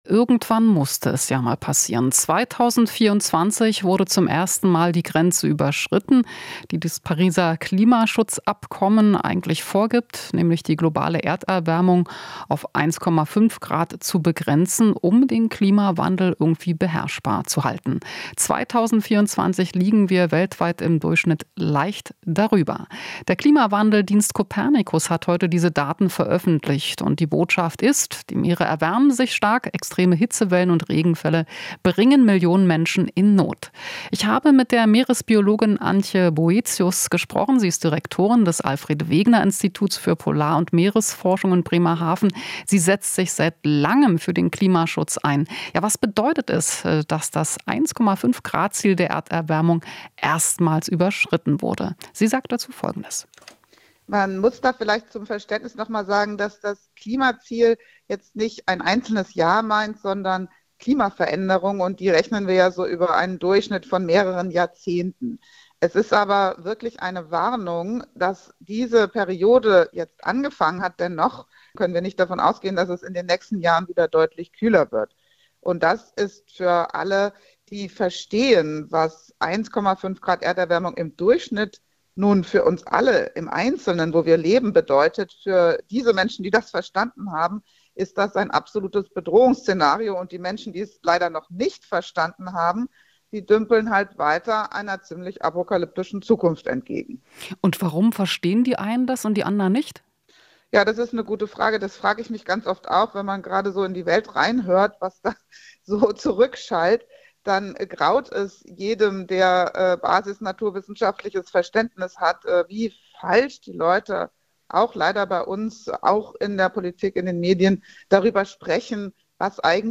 Interview - Meeresbiologin: Kühlere Temperaturen nicht in Sicht
Das Jahr 2024 war laut dem neuen Copernicus-Bericht das wärmste, seitdem Messdaten erhoben werden. Meeresbiologin Antje Boetius sagt, es sei eine Warnung, dass die Erderwärmung erstmals über 1,5 Grad lag.